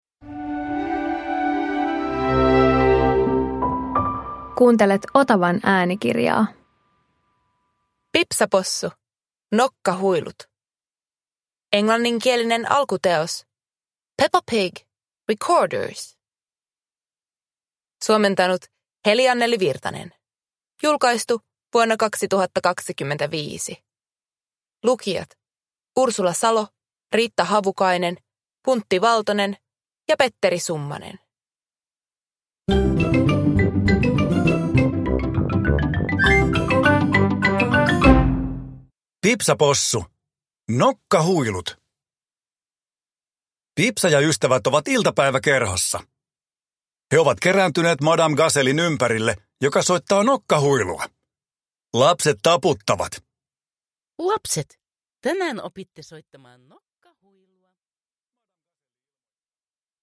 Pipsa Possu - Nokkahuilut – Ljudbok